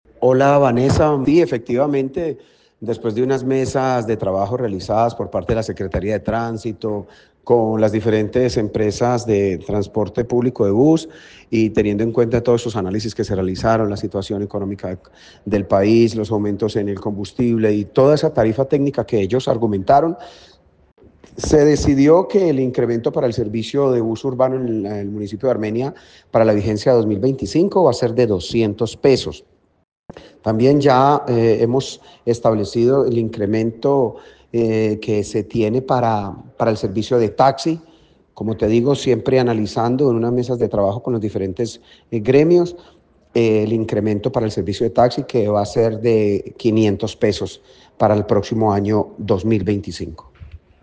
Alcalde de Armenia, James Padilla
En diálogo con Caracol Radio el alcalde de la capital quindiana, James Padilla explicó que tras unas mesas que fueron establecidas con las diferentes empresas de transporte público y teniendo en cuenta los análisis financieros tomaron la decisión de que el incremento en el pasaje de bus urbano será de $200 y para el servicio de los taxis será de $500.